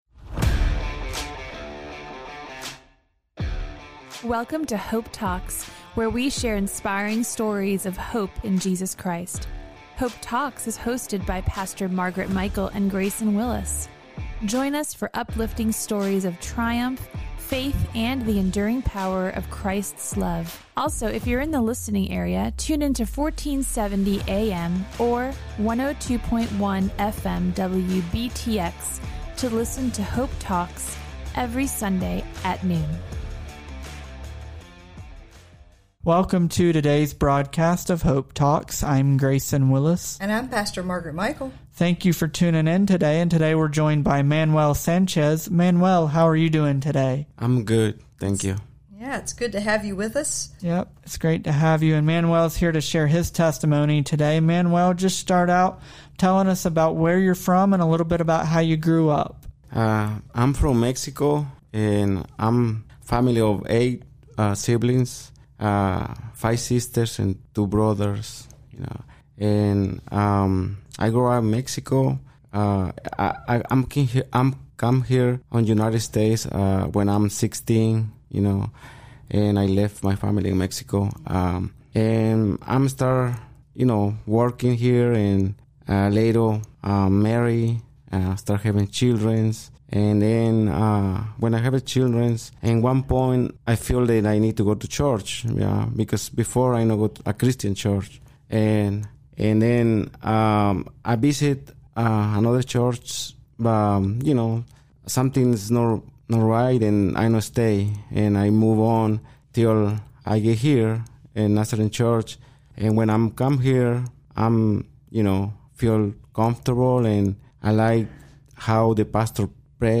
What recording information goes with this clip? We pray that today’s broadcast will be a half hour of Hope for your life.